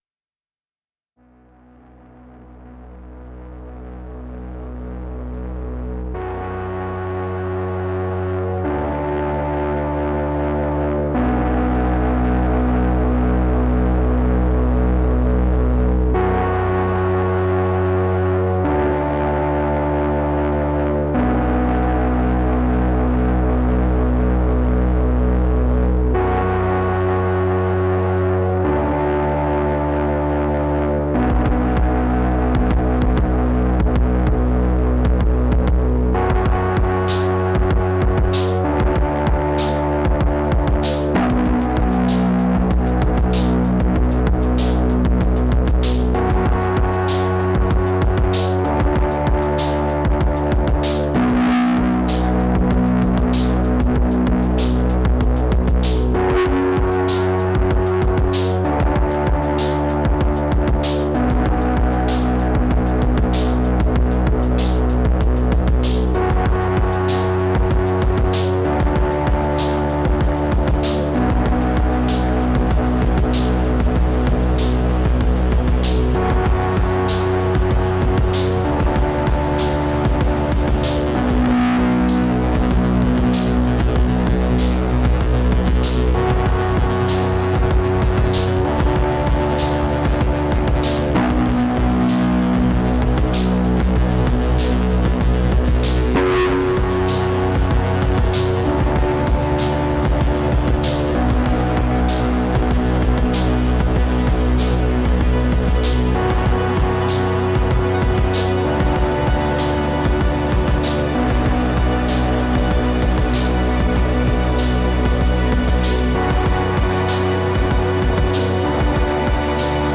Been using my syntakt to make some dark vibey triphop/ambient loopy stuff this week and these songs could really be more fully formed but I love the sounds I’m getting.
Syntakt with Artemis